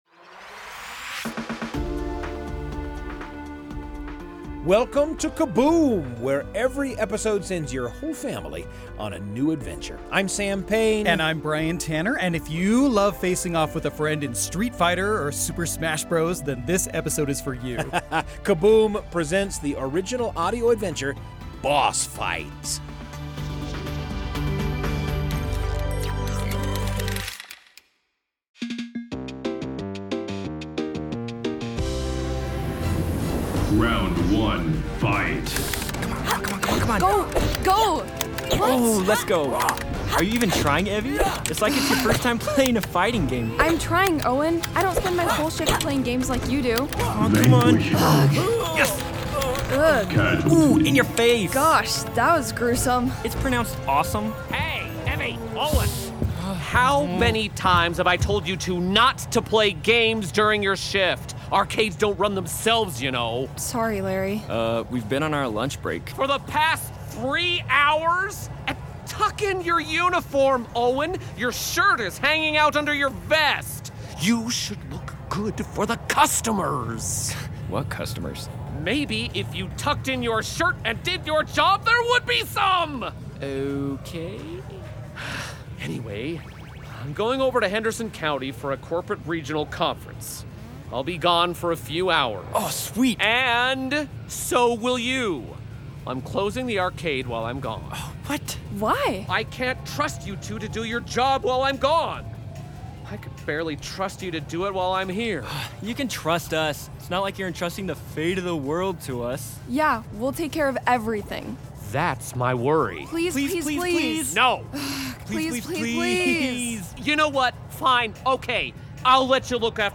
Kaboom: An Audio Adventure Podcast